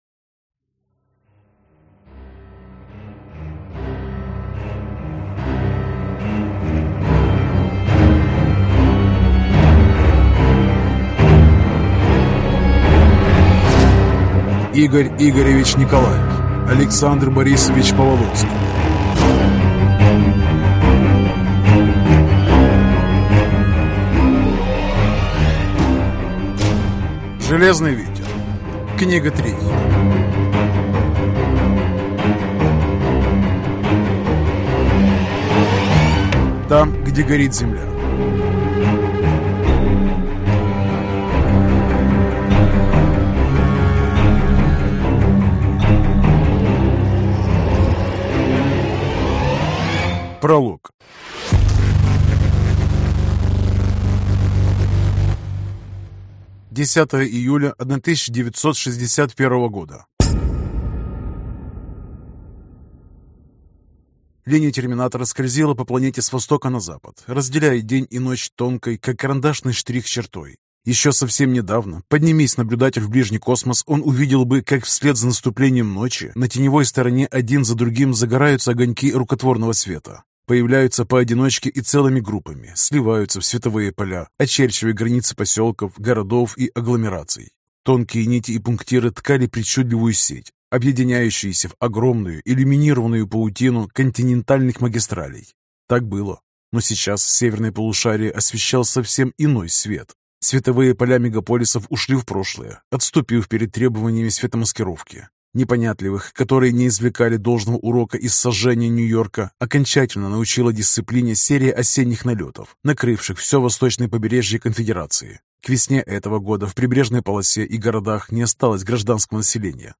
Аудиокнига Там, где горит земля | Библиотека аудиокниг